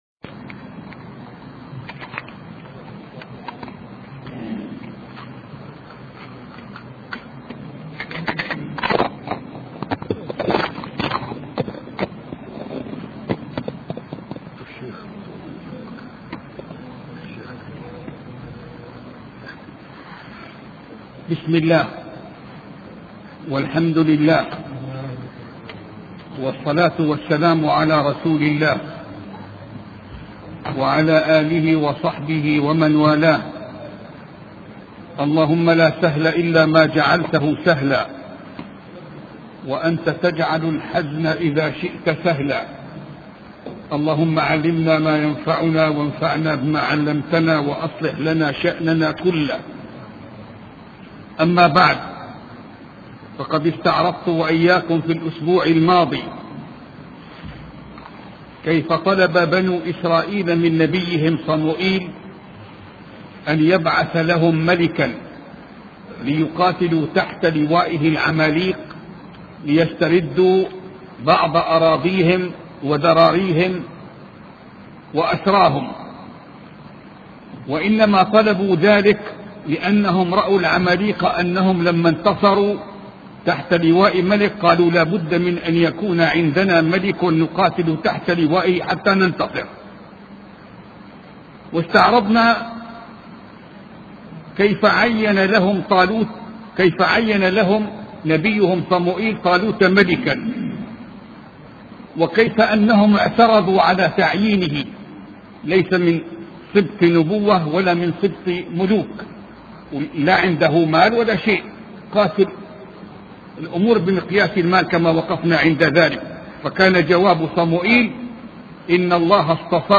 سلسلة محاضرات في قصة داود عليه السلام